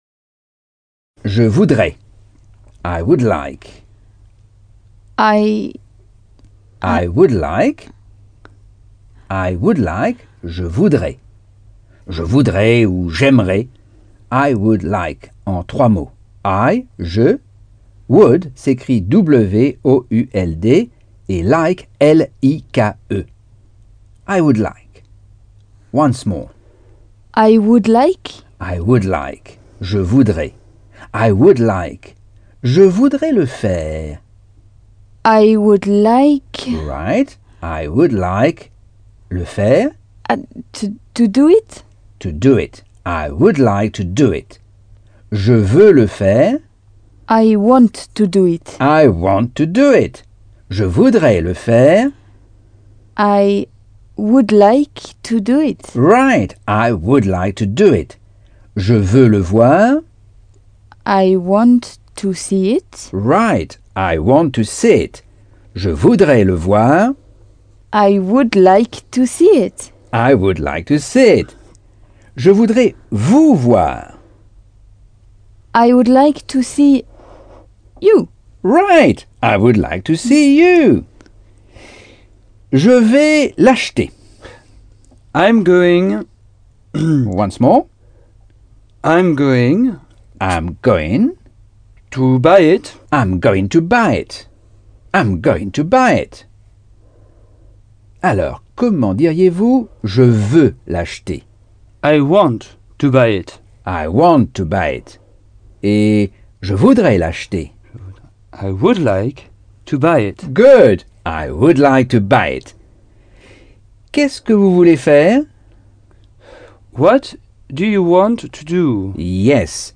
Leçon 1 - Cours audio Anglais par Michel Thomas